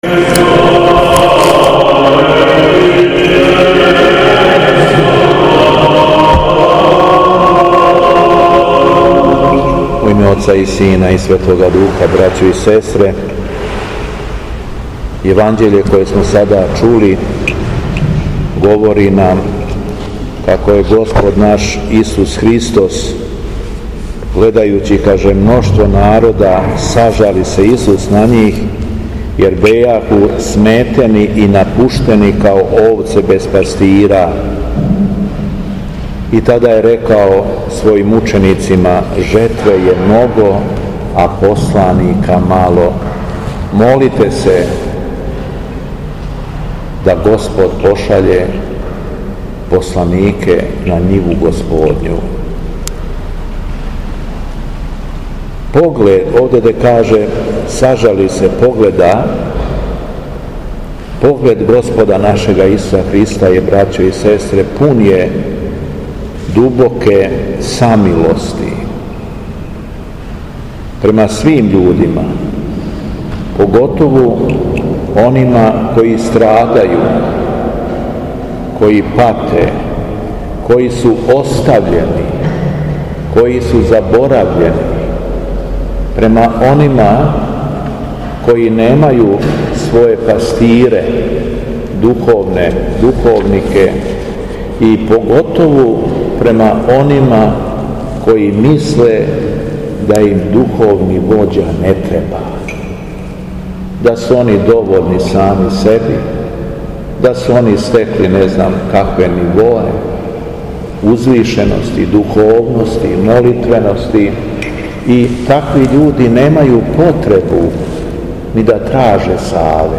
Беседа Његовог Преосвештенства Епископа шумадијског г. Јована
У понедељак трећи по Духовима, Његово Преосвештенство Епископ шумадијски Господин Јован служио је свету архијерејску литургију у храму Светога Саве у крагујевачком насељу Аеродром.